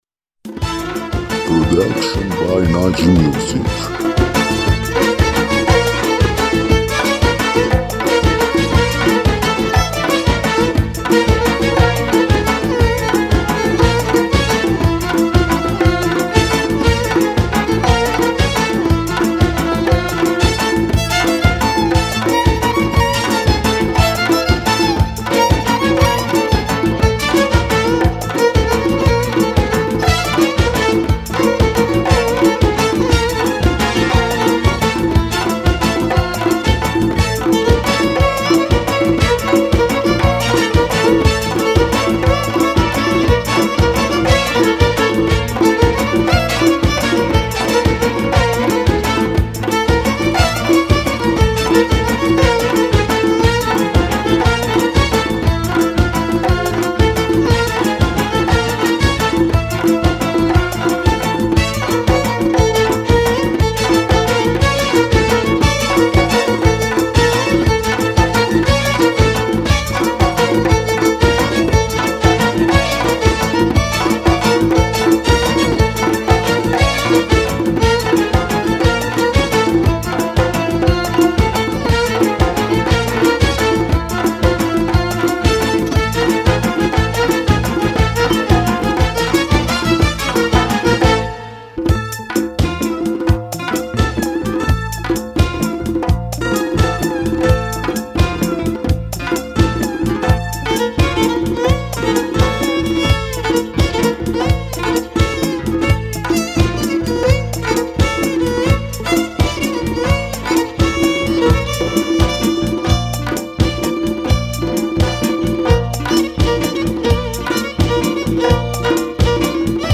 آهنگ بیکلام ملایم